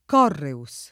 k0rreuS]; acc. sull’-e- più freq. in genere, dovuto a un’interpretaz. della voce come comp. dell’it. reo, inteso nel senso di «imputato» (non anche di «convenuto»)